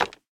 resin_brick_place2.ogg